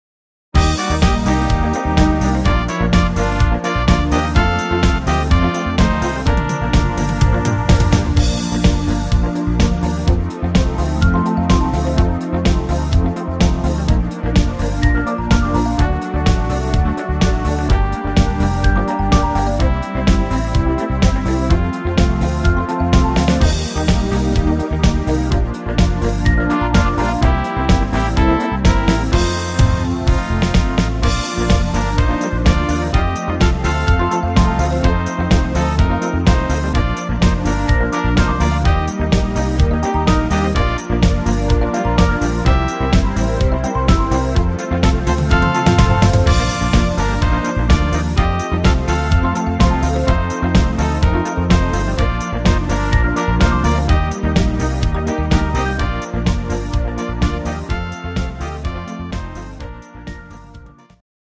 Rhythmus  Foxtrott
Art  Volkstümlicher Schlager, Deutsch